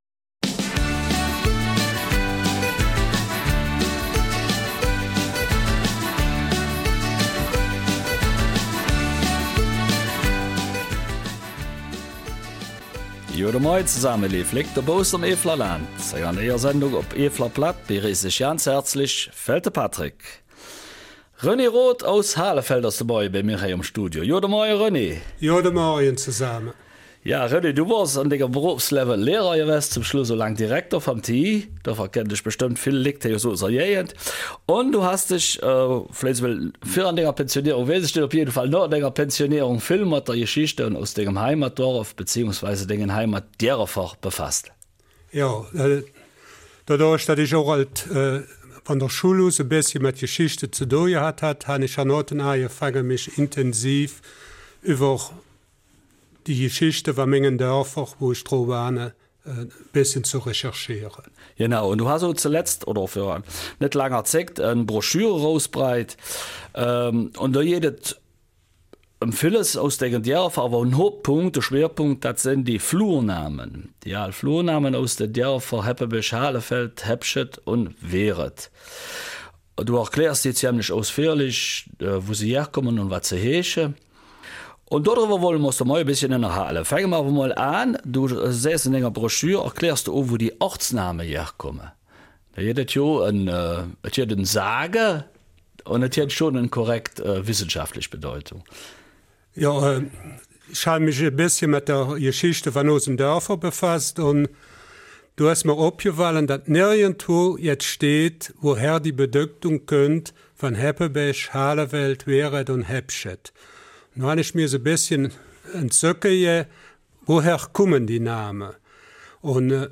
Eifeler Mundart: Wissenswertes aus Heppenbach, Halenfeld, Hepscheid und Wereth